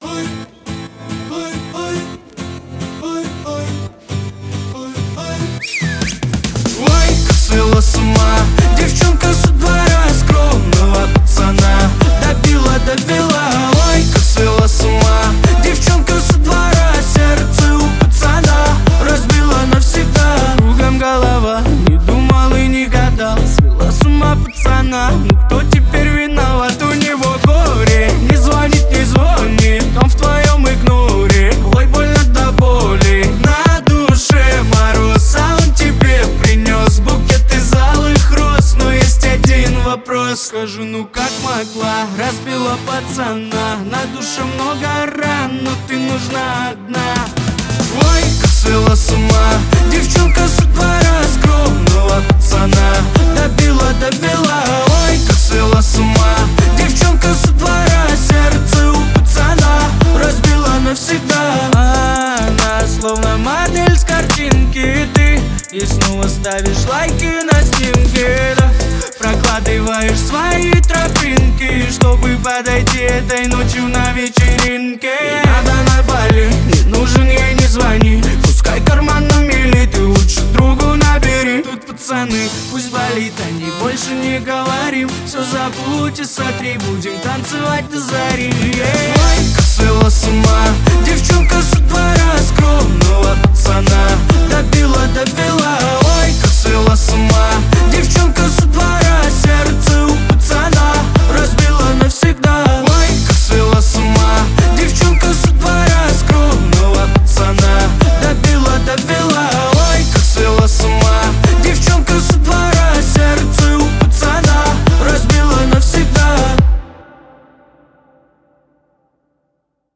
Танцевальная музыка
Dance музыка